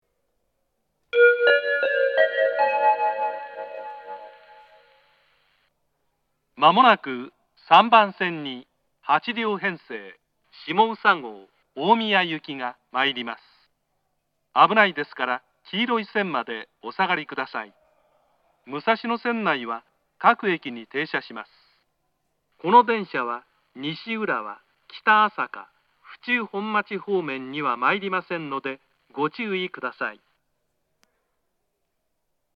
接近放送
8両編成 しもうさ号 大宮行の接近放送です